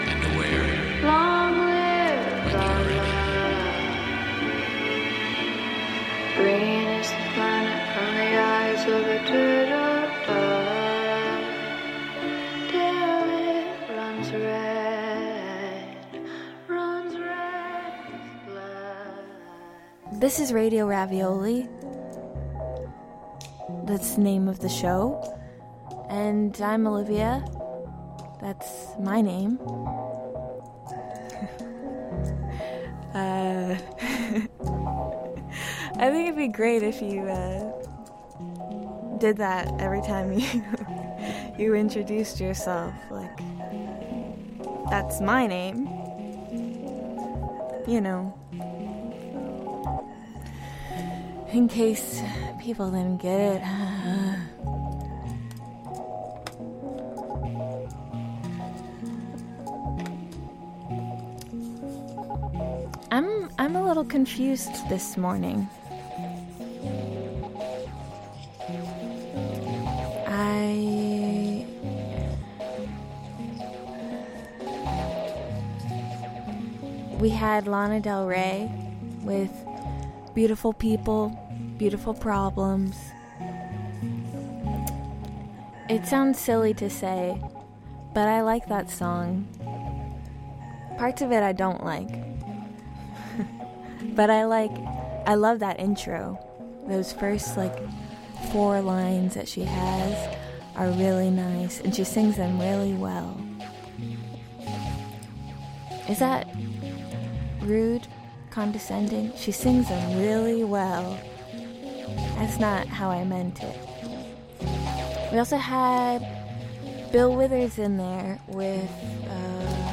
In a stew of intimate electronics, bad poetry, and tender murk, we swap tongues and reach for more.